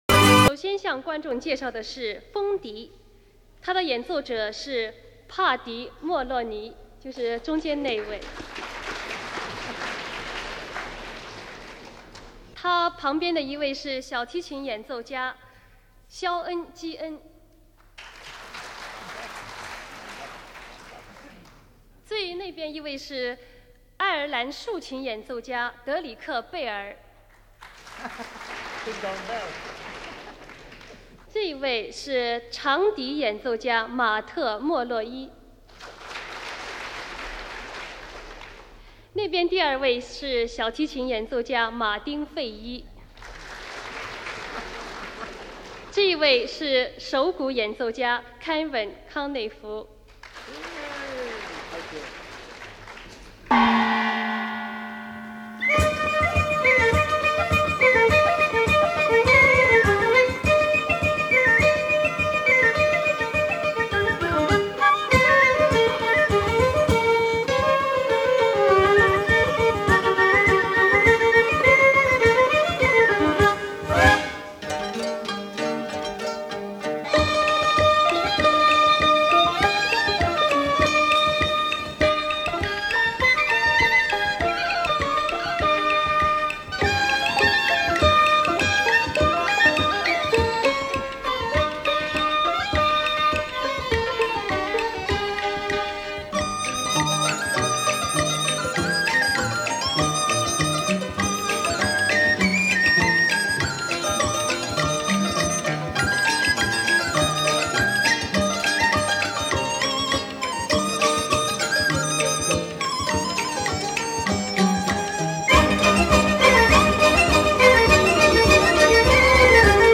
风格流派: Pop
综合了东西方音乐风格，运用了中国的乐器演奏
中国民乐乐团合奏